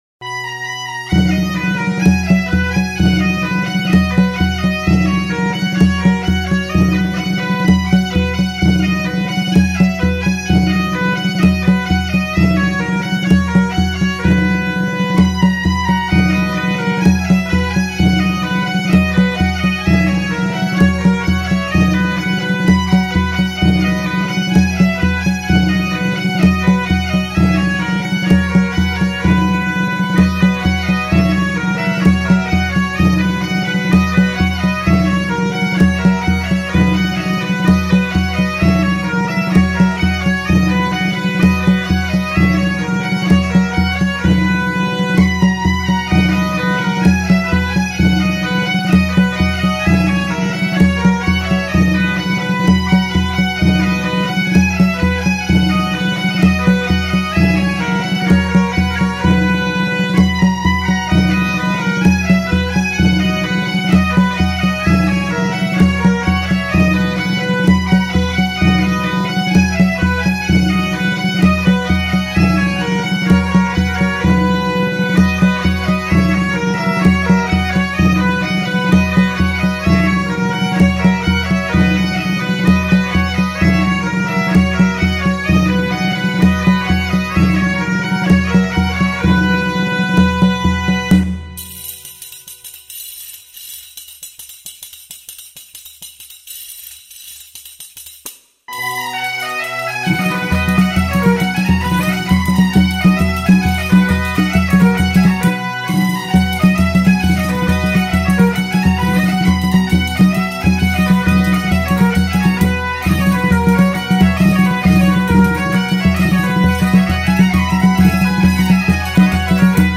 para ser interpretada pola banda de gaitas Manxadoira de Bueu
Muiñeira Opus 32. Adaptación para gaita pola banda de gaitas Manxadoira (MP3)
muineira-bandagaitasmanxadoira5_1.mp3